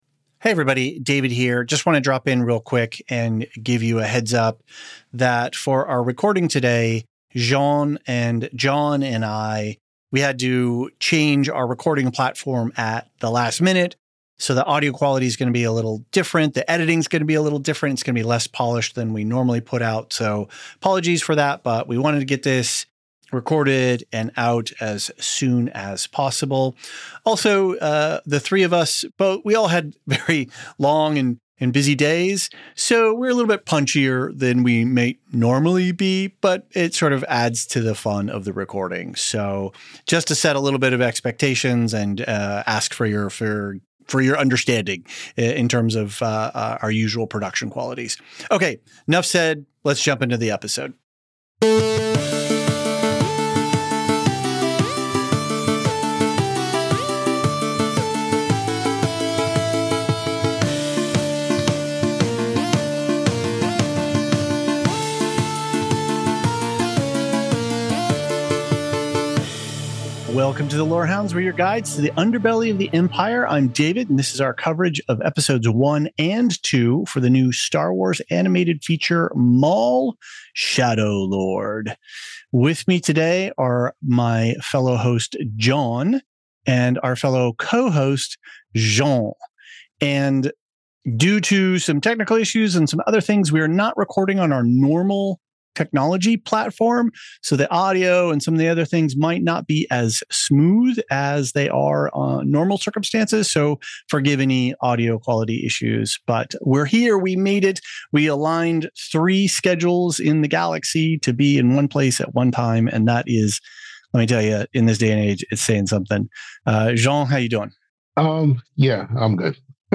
A quick note — we had some technical issues with our usual recording setup this week, so the audio quality isn't up to our normal standards, but the conversation is very much worth the listen.